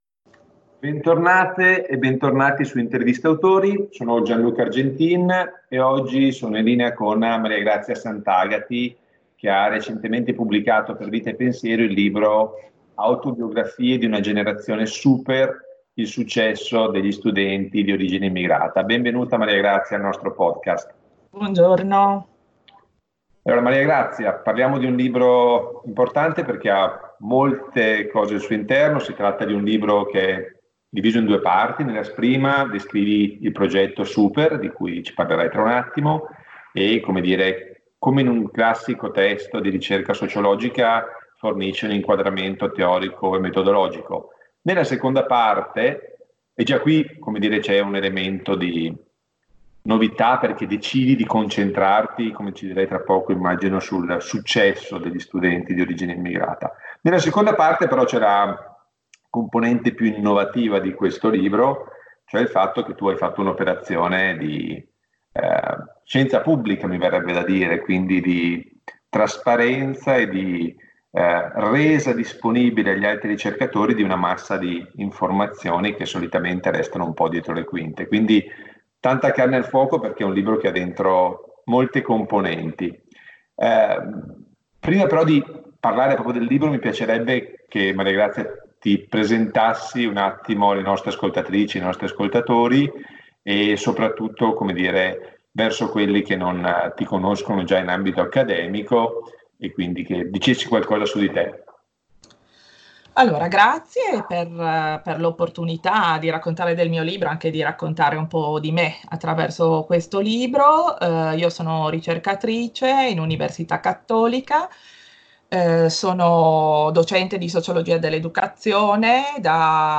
Nel Podcast di oggi intervistiamo